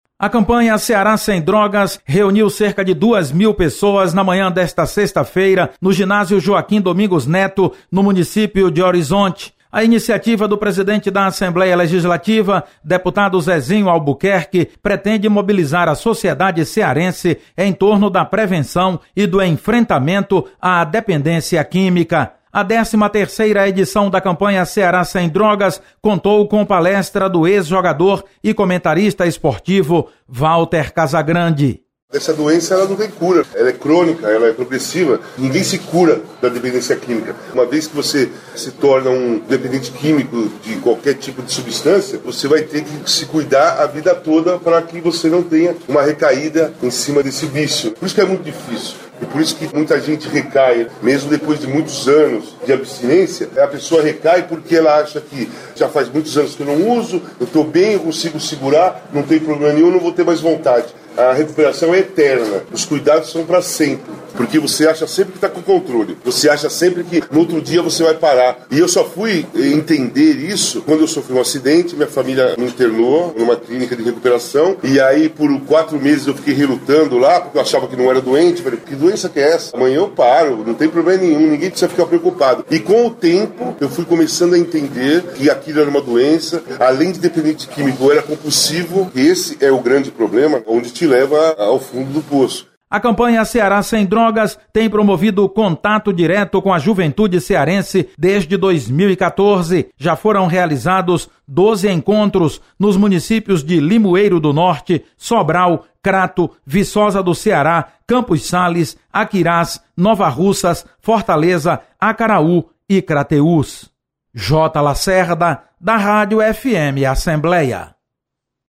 Você está aqui: Início Comunicação Rádio FM Assembleia Notícias Campanha